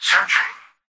"Searching" excerpt of the reversed speech found in the Halo 3 Terminals.